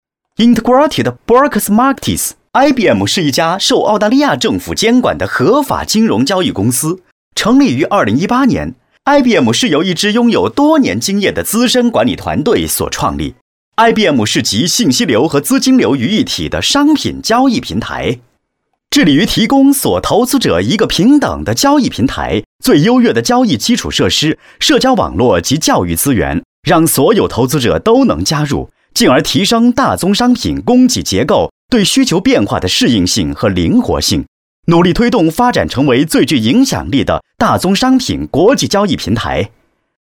金融mg男36号
年轻时尚 MG动画